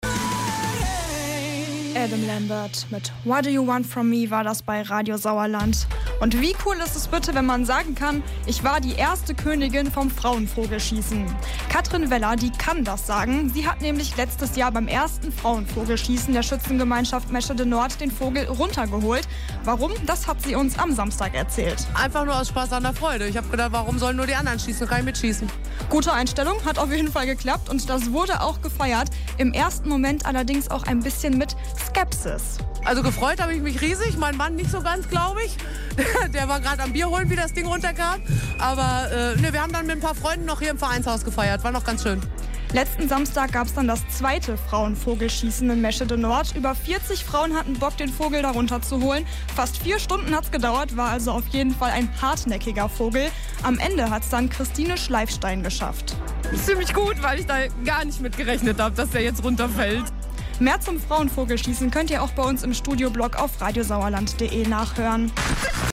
mitschnitt-frauenvogelschiessen.mp3